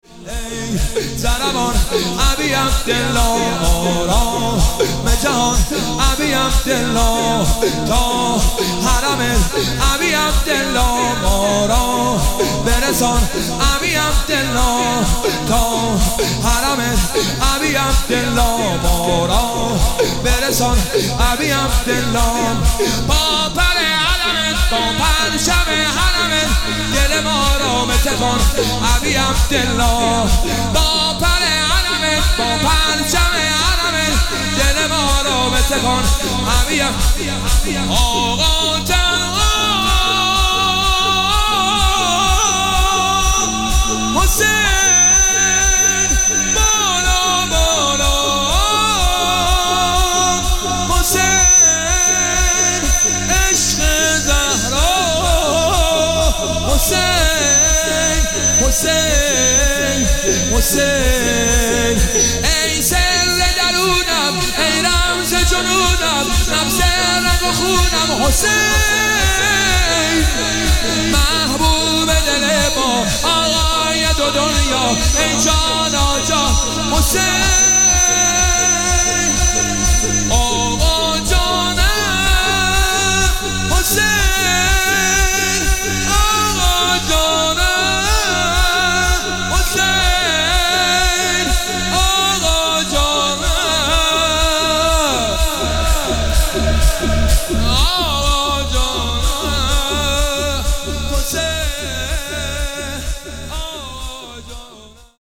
محفل عزاداری شب نهم محرم